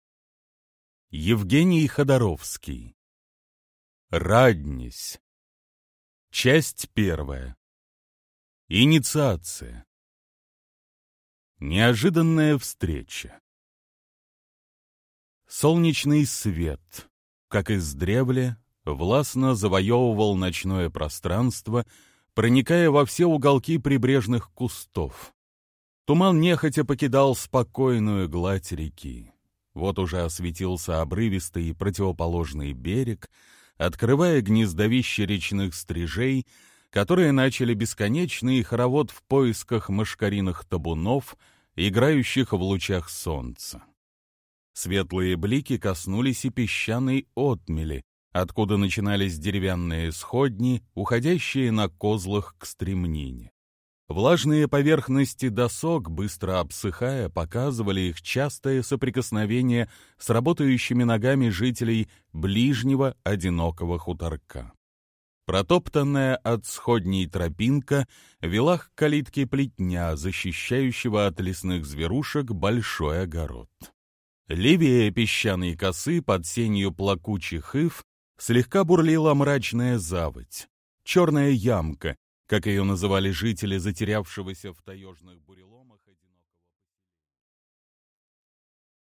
Аудиокнига Раднесь | Библиотека аудиокниг